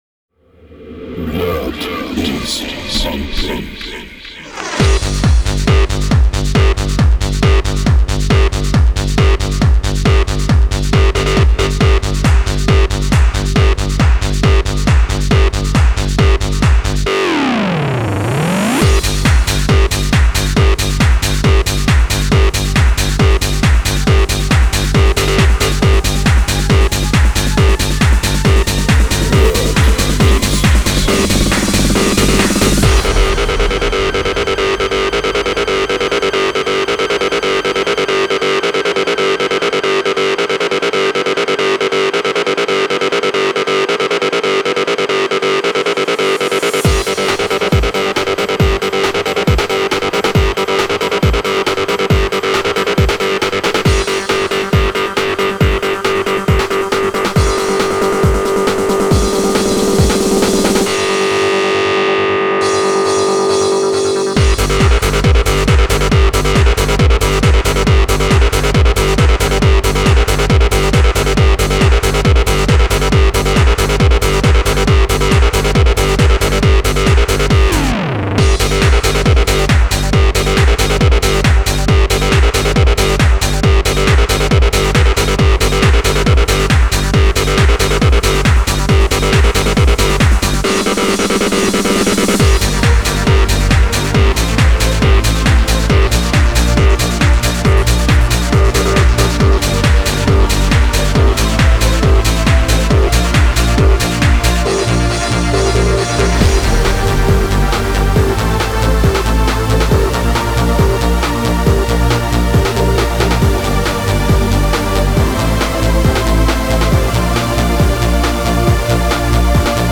Genre: Trance.